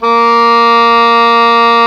WND PBOE A#3.wav